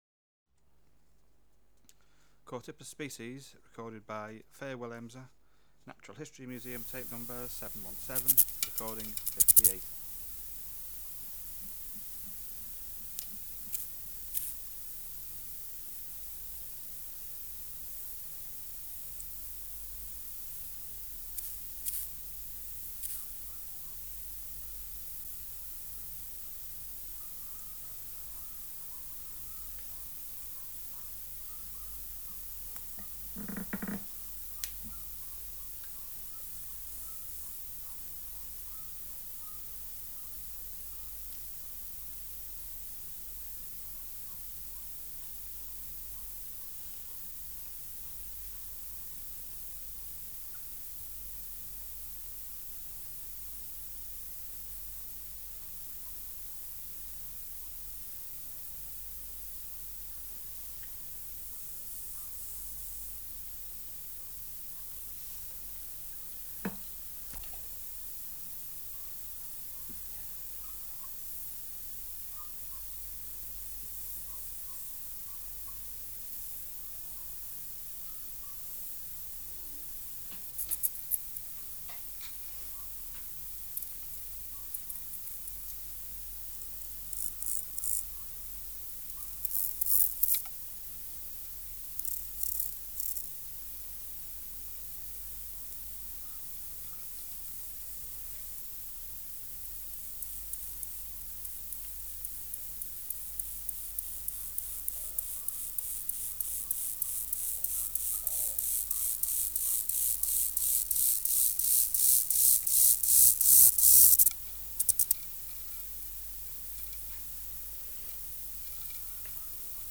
Species: Chorthippus